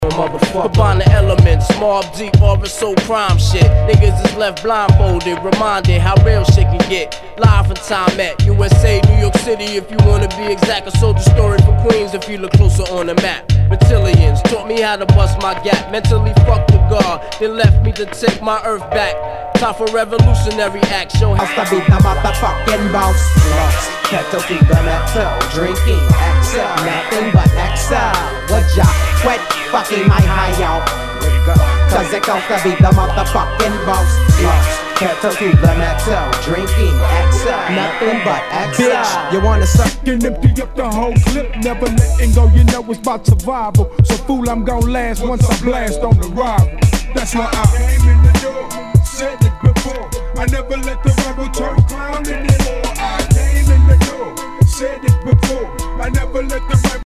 HIPHOP/R&B
ジャケにスレキズ、角潰れ、破れあり、全体に地理ノイズが入ります